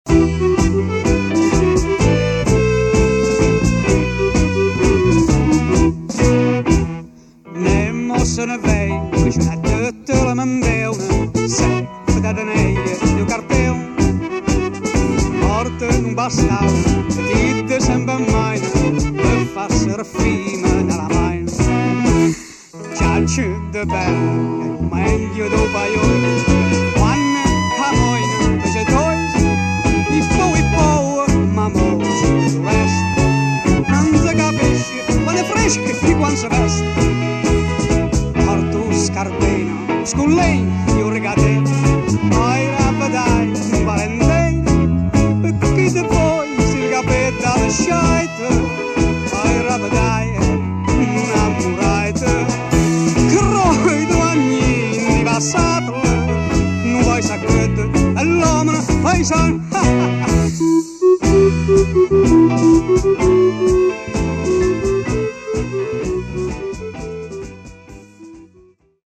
oggi viene riproposto in versione rimasterizzata.